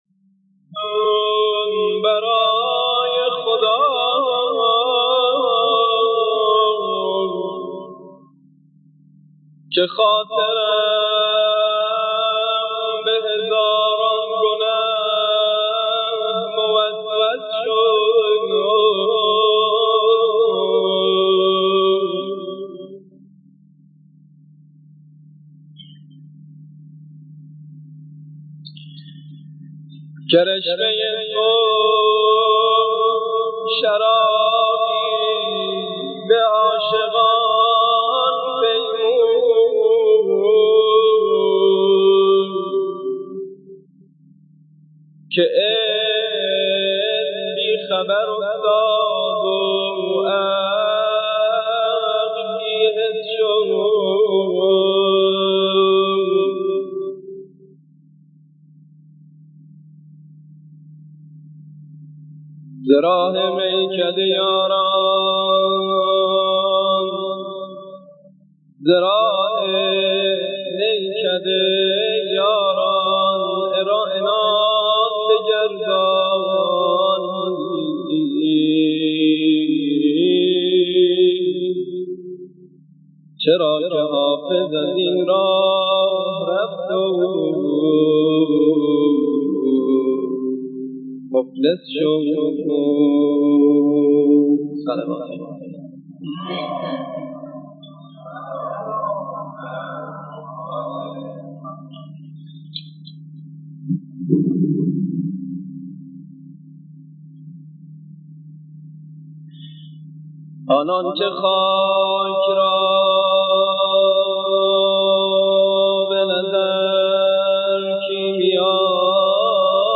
مدیحه خوانی در ولادت حضرت رسول اکرم صلی اللَه علیه و اله و سلم 17 ربیع الأول سال 1418 هـ.ق